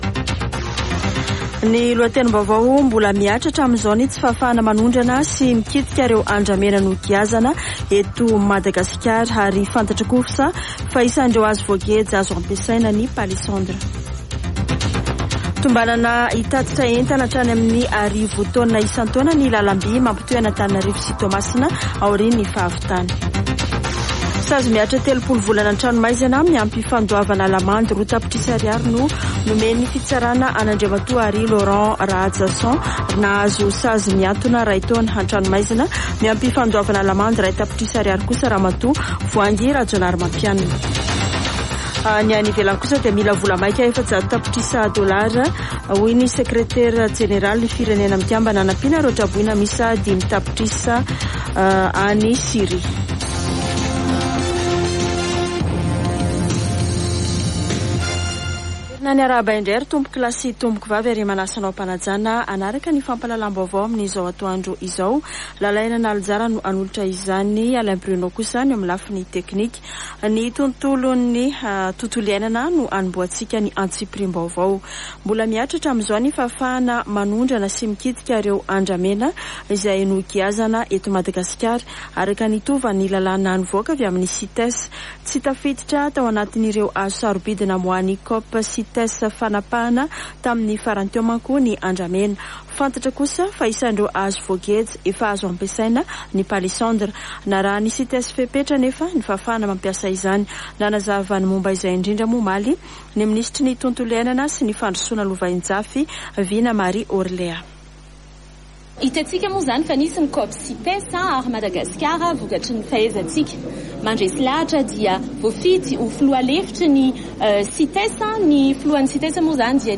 [Vaovao antoandro] Alarobia 15 febroary 2023